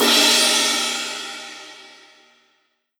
S_ride2Crash_2.wav